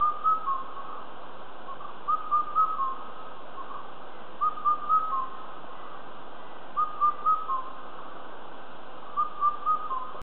C-micropterus.ogg